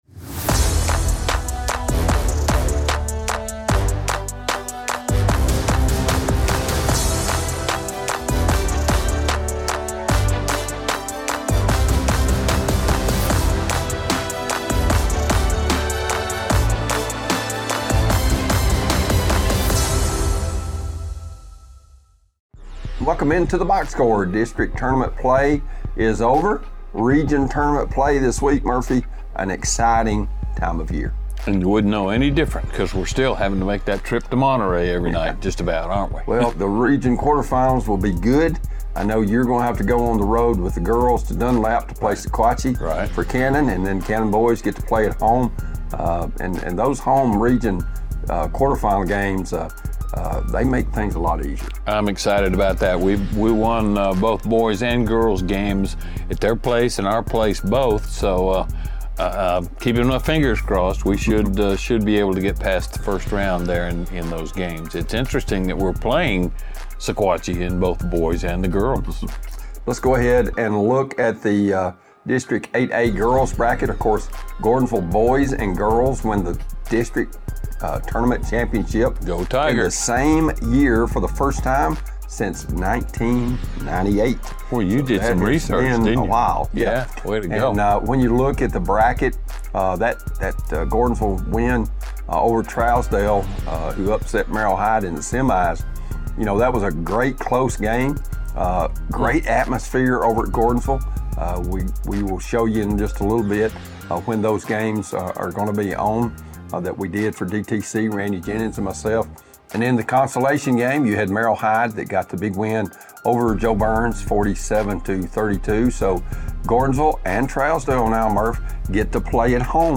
The coaches share team philosophies emphasizing brotherhood, community support, and clutch preparation, while hosts preview region brackets in Districts 8-A, 6-AA, and 8-AAA. Topics cover Watertown's girls' home-court edge, Gordonsville's girls' and boys' district titles, and predictions for matchups.